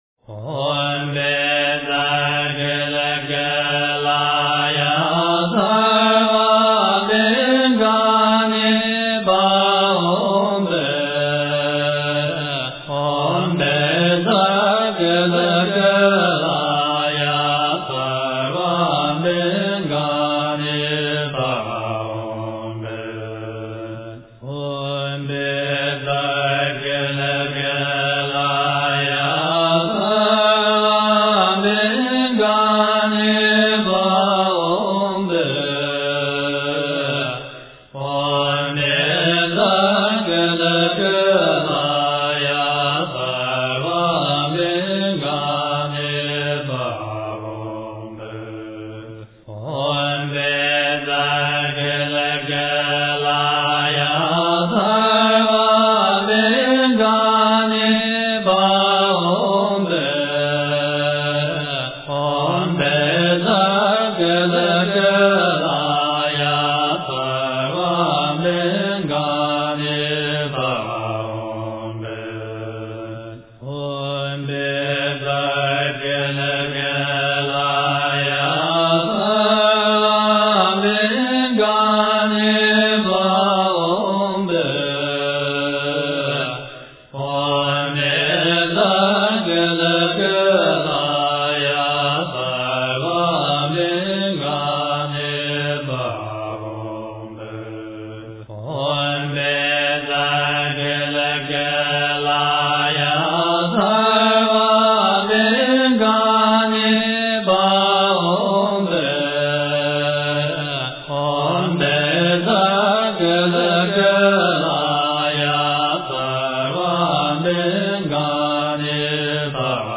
真言
佛音
佛教音乐